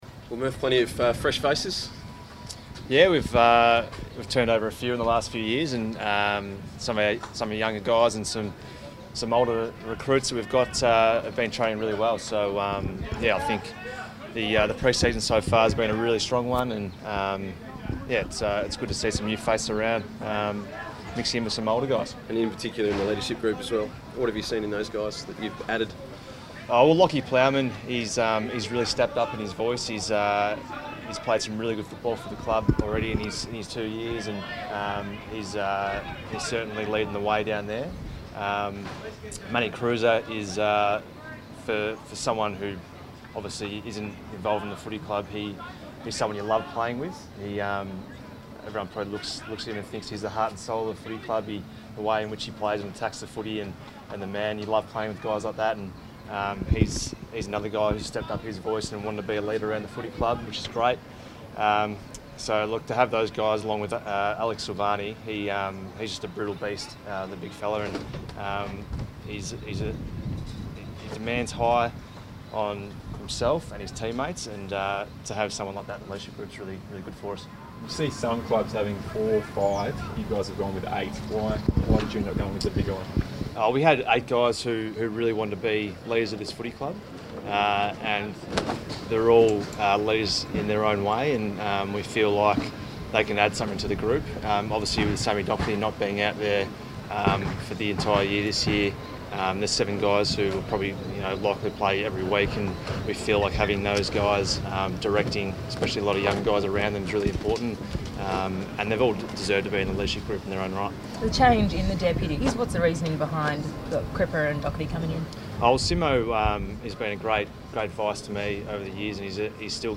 Marc Murphy press conference | January 31